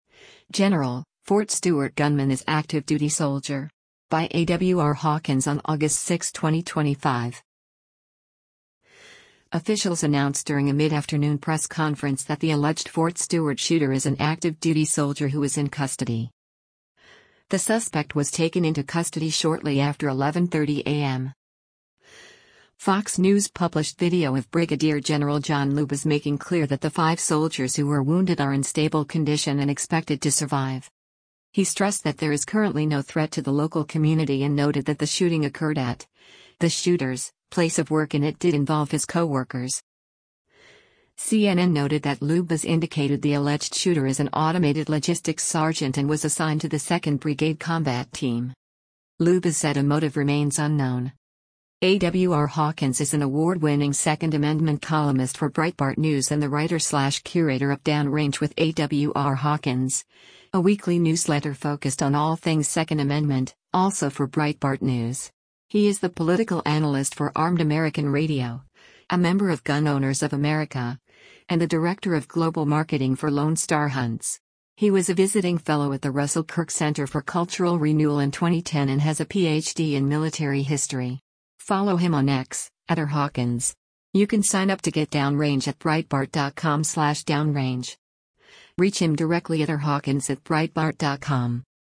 Officials announced during a midafternoon press conference that the alleged Fort Stewart shooter is an active duty soldier who is in custody.
FOX News published video of Brig. Gen. John Lubas making clear that the five soldiers who were wounded are in stable condition and expected to survive.